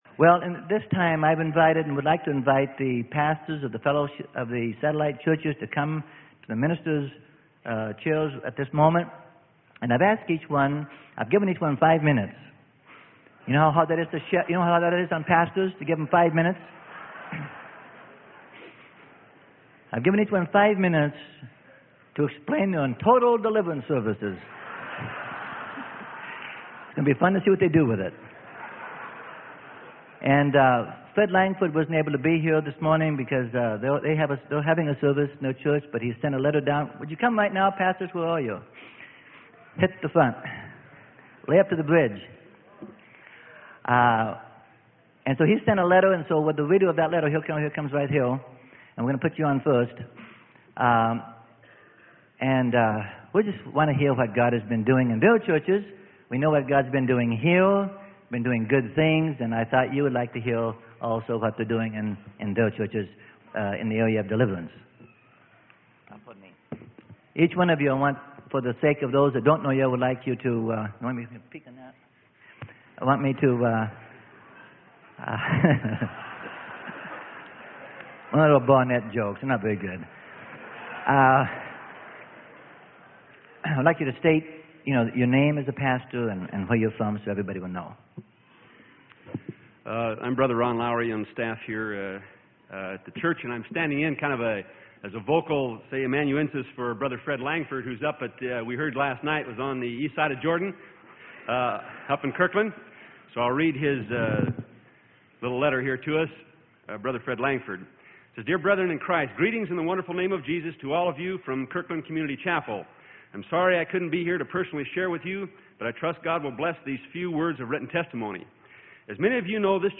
Sermon: EIGHT SERMONETTES - Freely Given Online Library